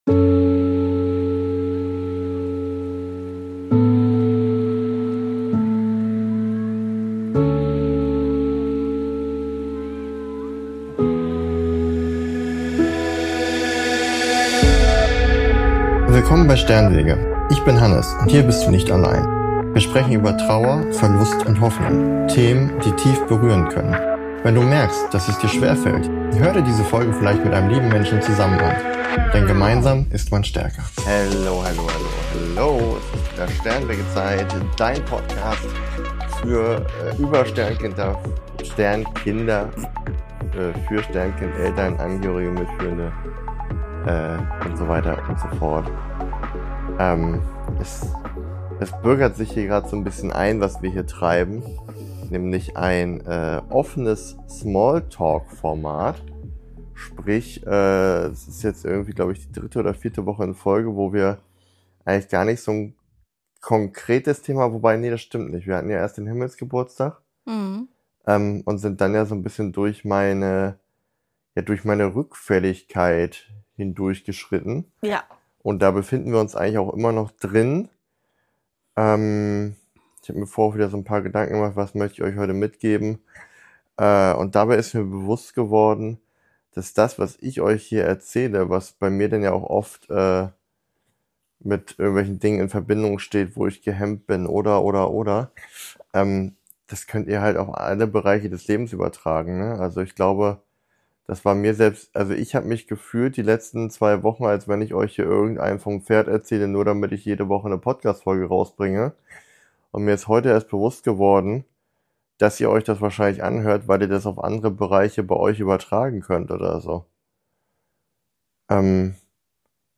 Eine weitere ehrliche Smalltalk-Folge – ohne großes Thema, mit viel Leben dazwischen. Ich spreche offen über mein Impostor-Syndrom, den Mut zum Jobwechsel und warum es manchmal ein „Ende mit Schrecken“ braucht, um endlich anzufangen.